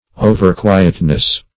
overquietness - definition of overquietness - synonyms, pronunciation, spelling from Free Dictionary
Overquietness \O"ver*qui"et*ness\, n. Too much quietness.